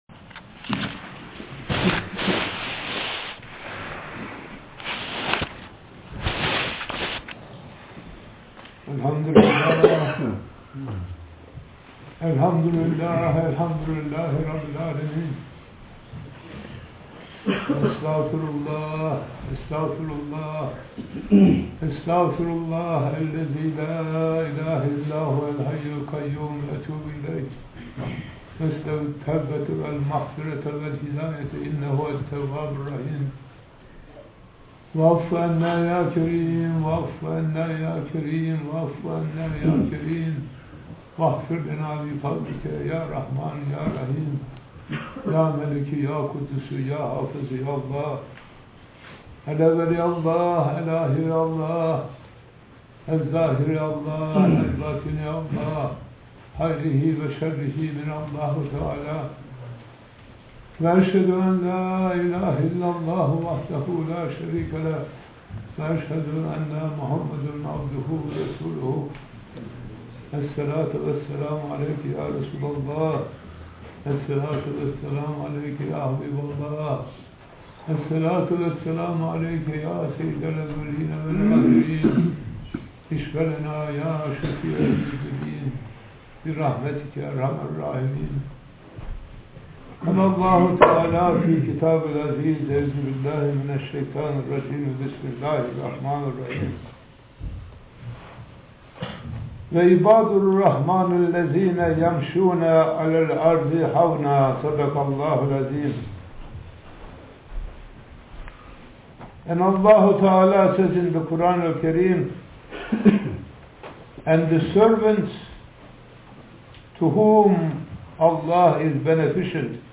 audio_hutba